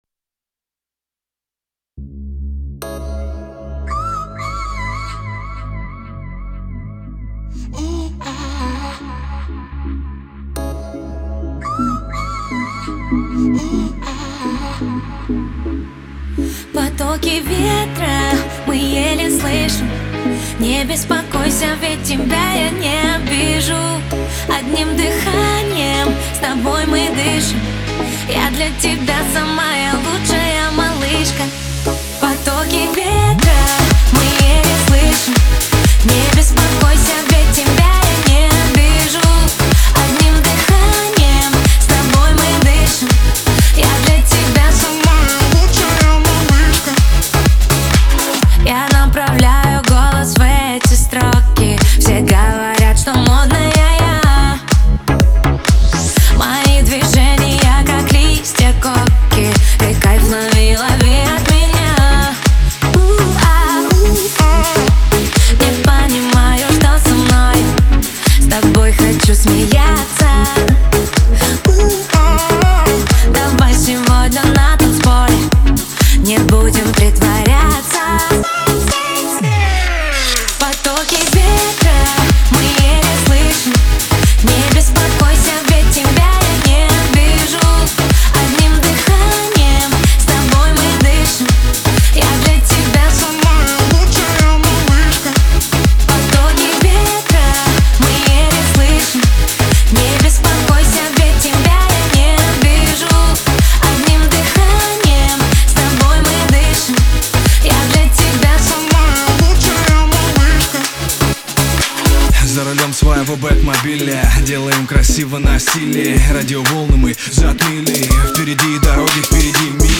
Главная » Файлы » Поп Музыка Категория